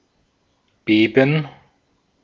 Ääntäminen
France (Paris): IPA: /tʁɑ̃.ble/